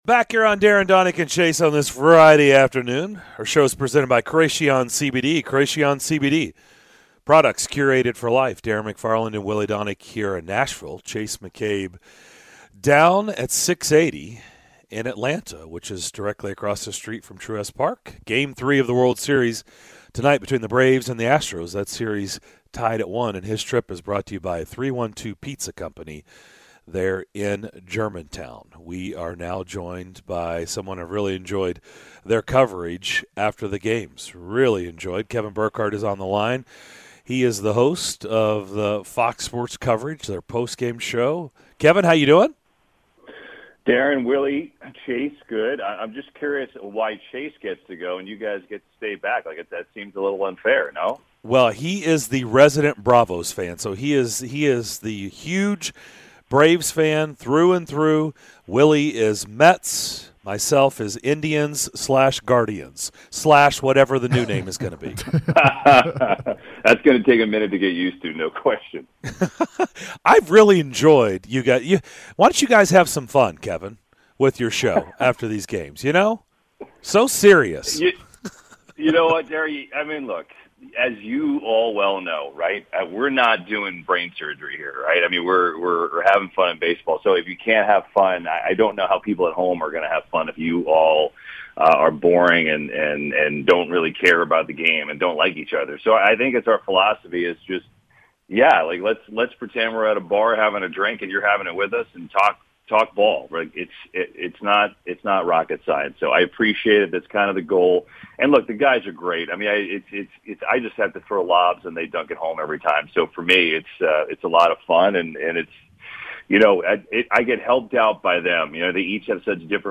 Fox Sports studio host Kevin Burkhardt joined the DDC to break down the World Series between the Braves and Astros!